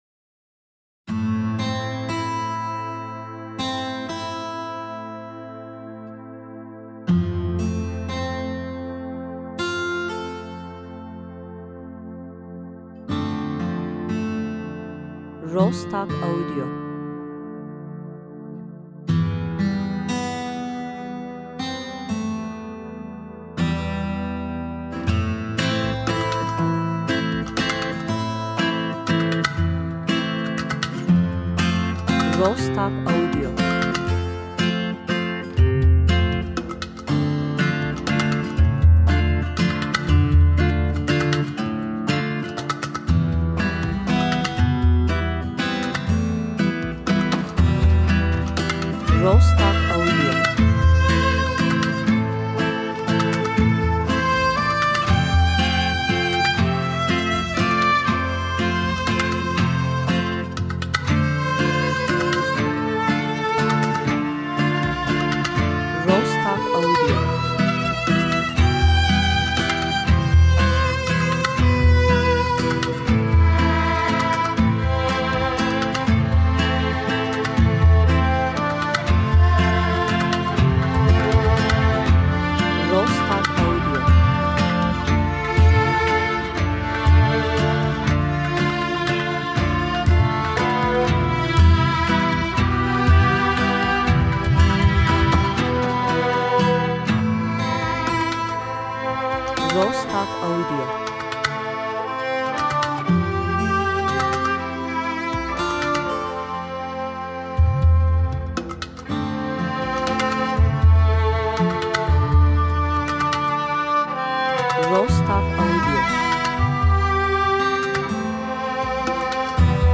enstrümantal müzik
epik epic